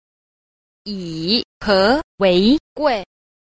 Cách đọc:
yǐ hé wéi guì.